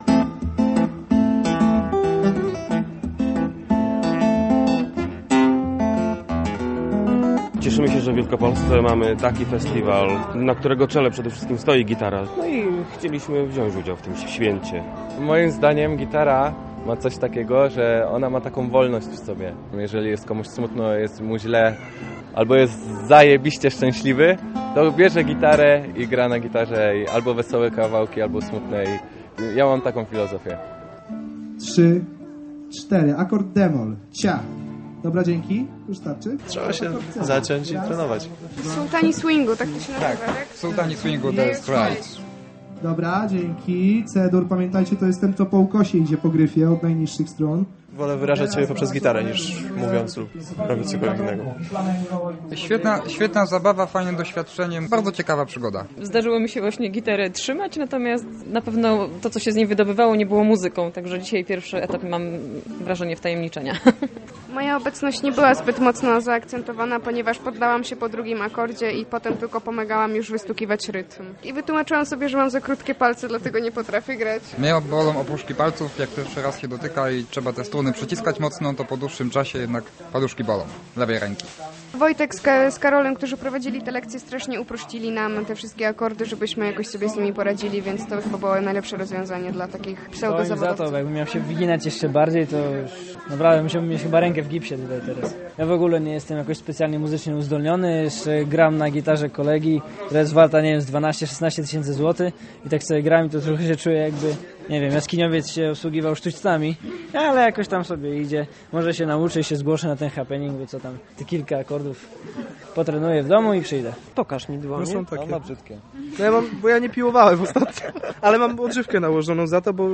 Sześć strun - reportaż
Nad Festiwalem Polska Akademia Gitary unosił się duch Dire Straits. Na Starym Rynku w Poznaniu gitarzyści-amatorzy zagrali utwór "Sultans of swing".